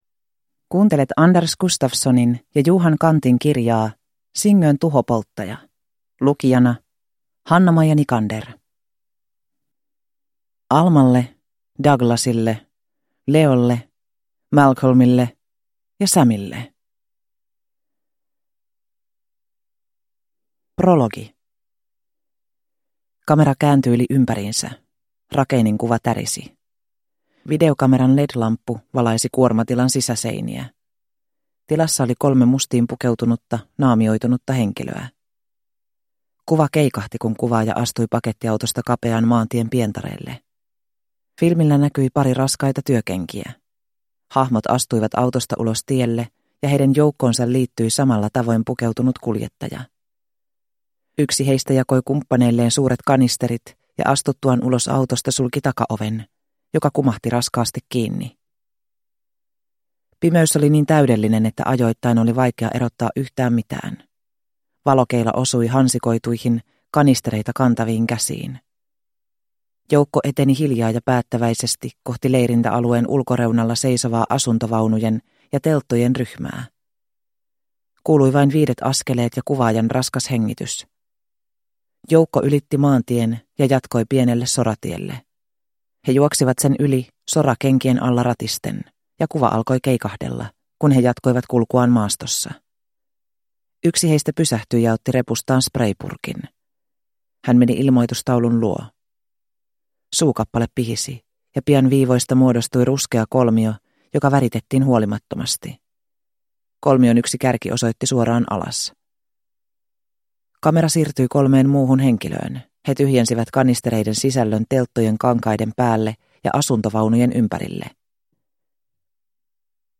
Singön tuhopolttaja – Ljudbok – Laddas ner